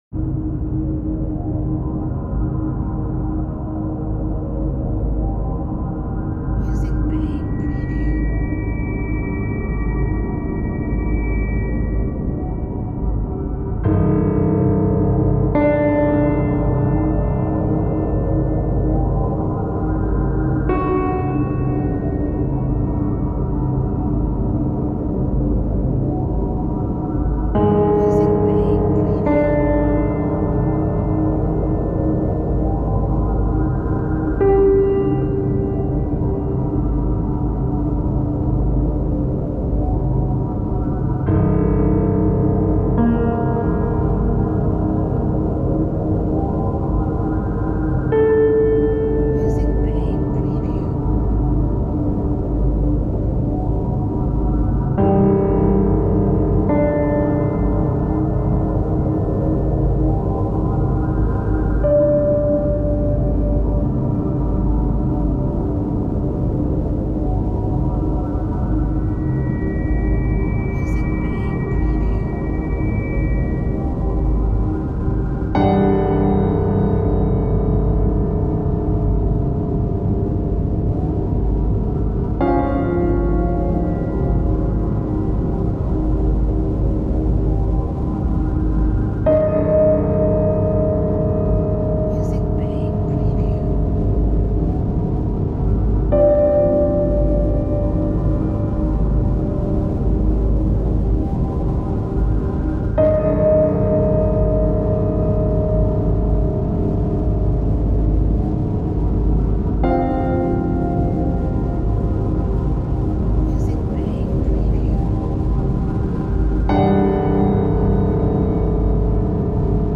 A category of tags that highlights horror